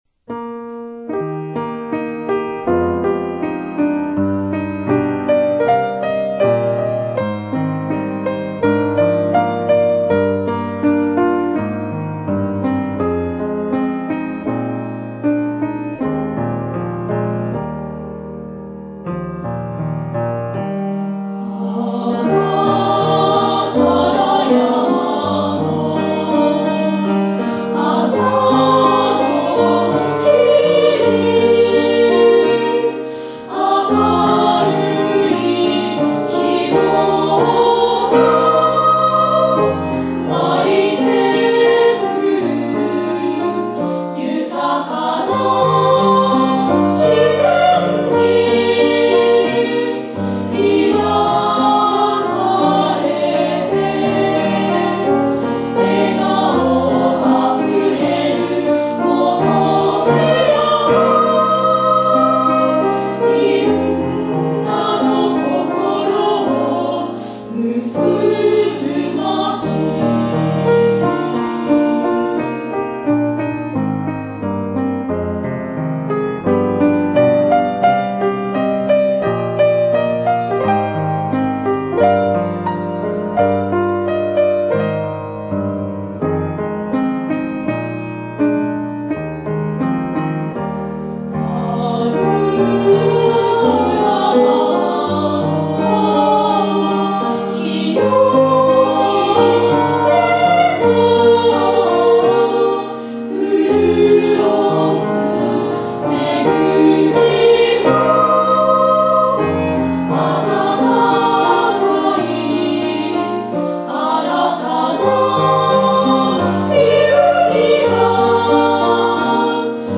独唱